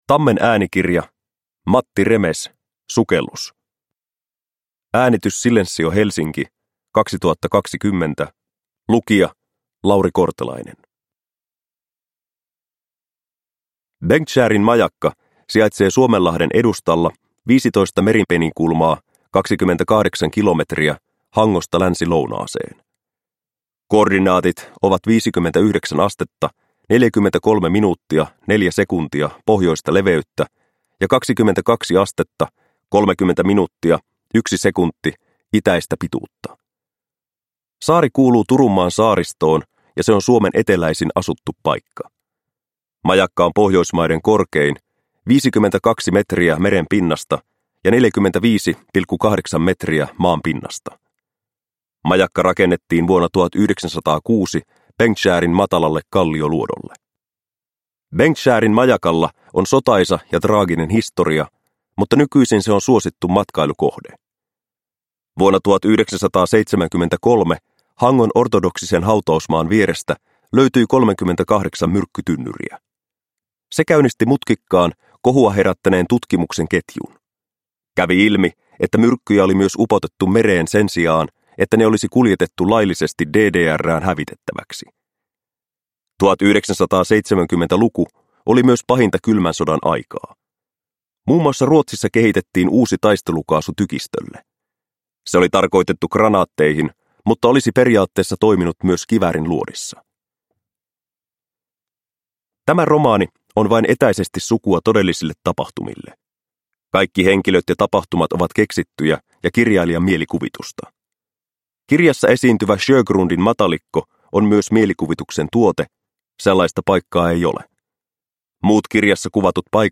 Sukellus – Ljudbok – Laddas ner